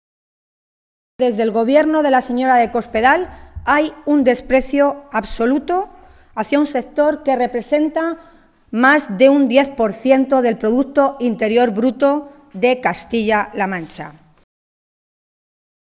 Milagros Tolón, diputada regional del PSOE de Castilla-La Mancha
Cortes de audio de la rueda de prensa